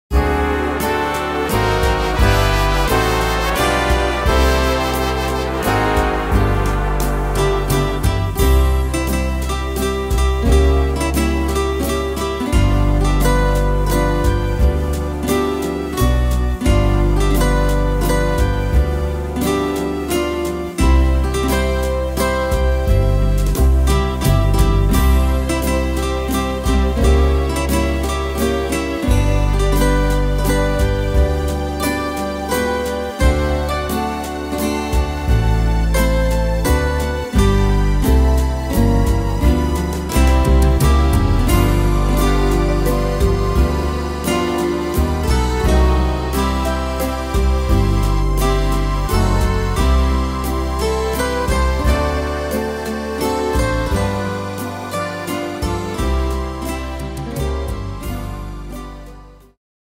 Tempo: 87 / Tonart: C-Dur